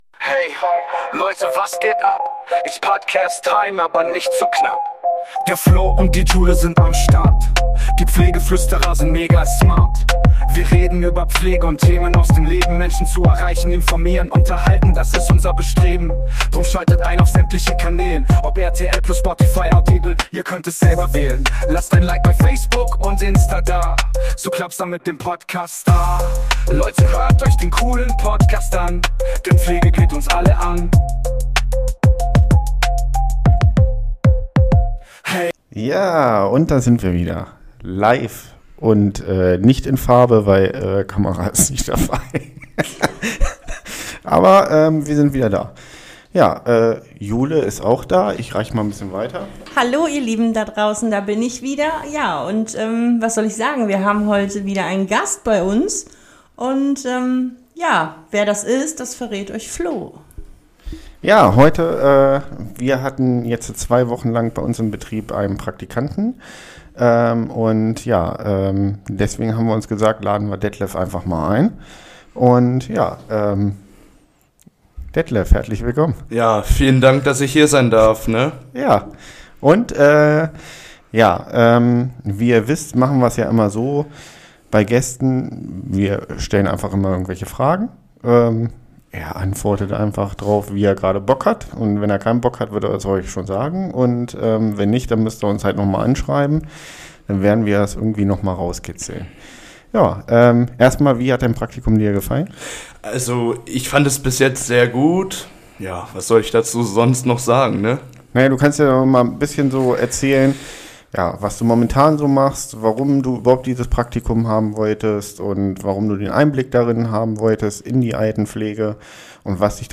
Wir hatten einen Praktikanten bei uns im Haus und er hat sich freundlicherweise getraut mal ein paar Worte zu sagen. Es immer wichtig auch Praktikanten in unseren Beruf mal reinschnuppern zu lassen.